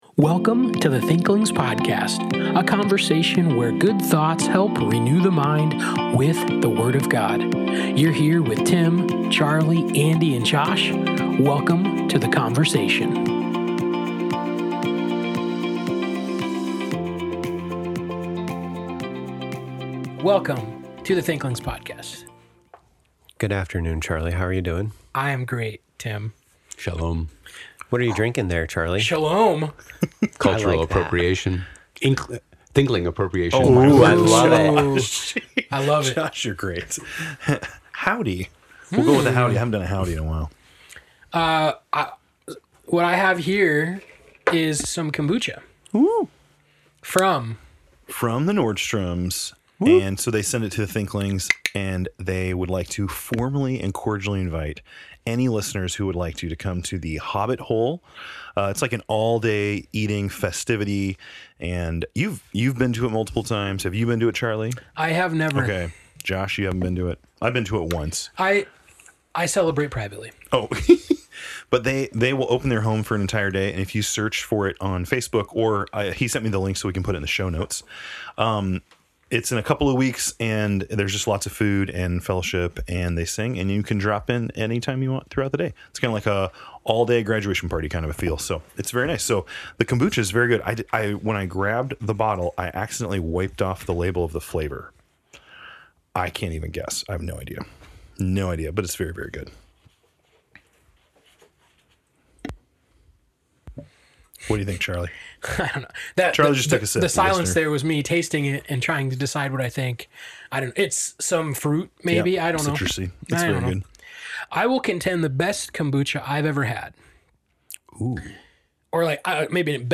Thanks for tuning in to this week’s conversation!